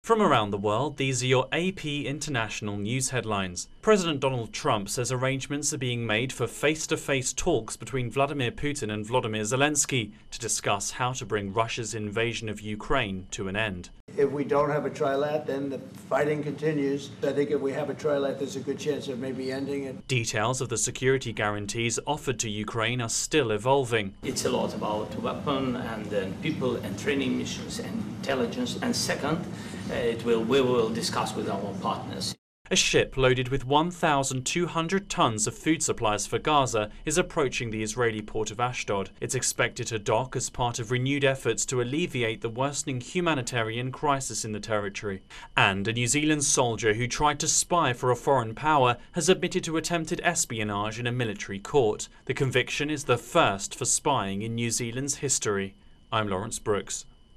AP International news headlines